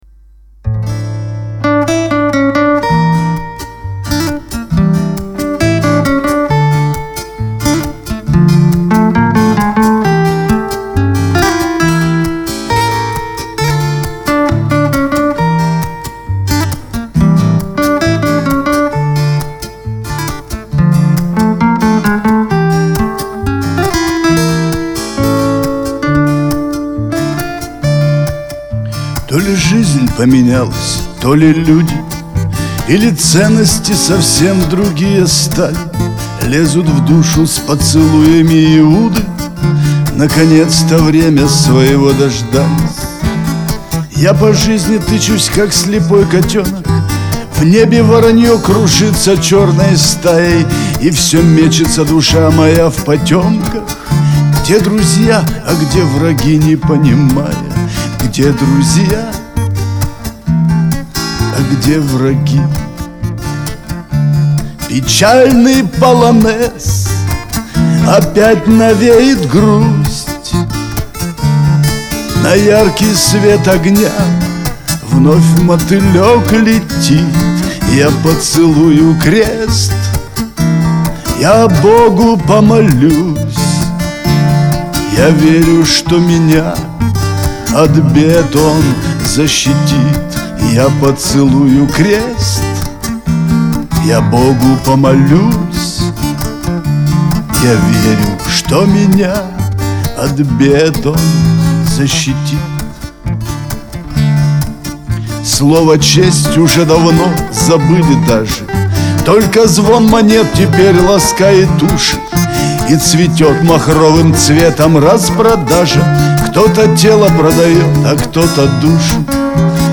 Пишет песни. Играет на гитаре.